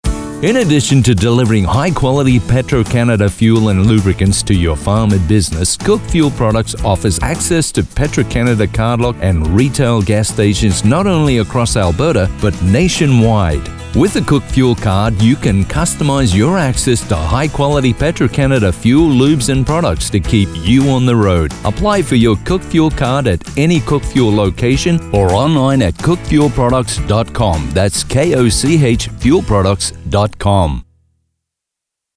Radio Spot
Australian, British, Southern US
Middle Aged